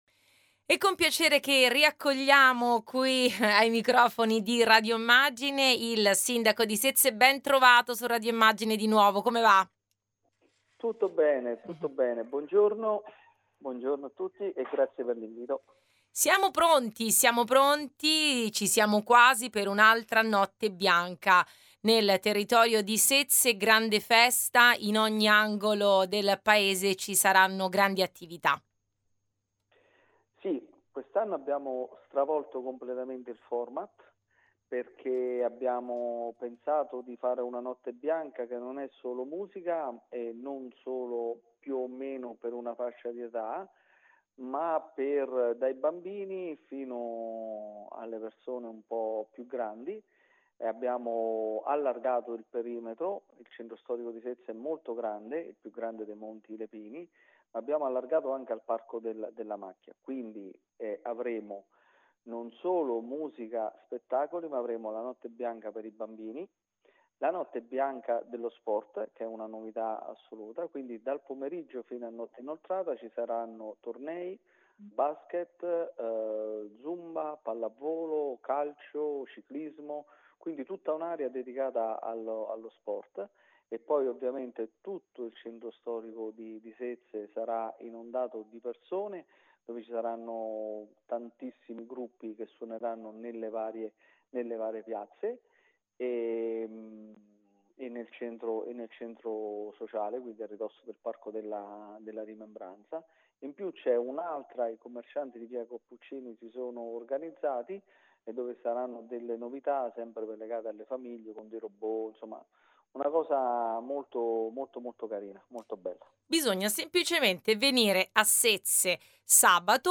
L’intervista al Sindaco Lidano Lucidi